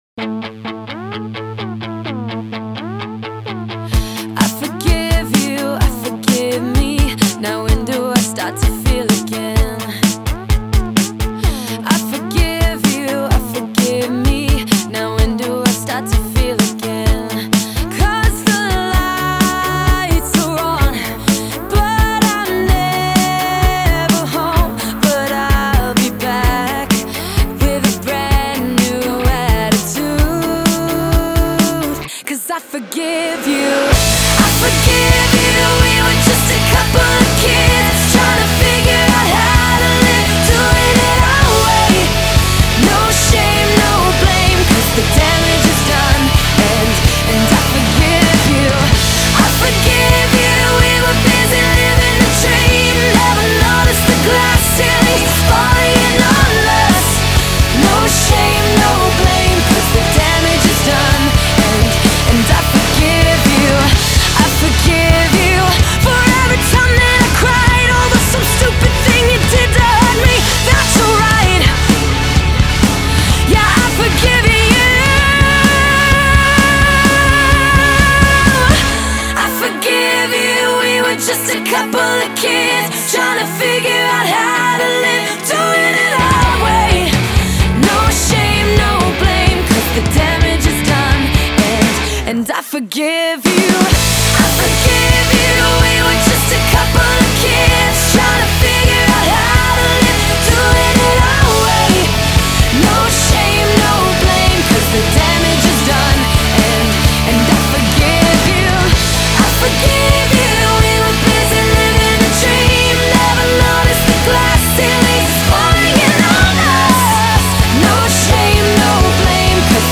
BPM64-128
Audio QualityMusic Cut